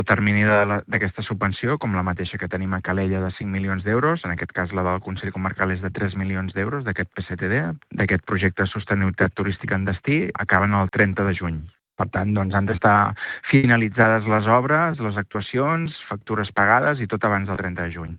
Pel que fa als terminis, Ponsdomènech ha recordat que les actuacions estan subjectes al calendari fixat per la subvenció europea: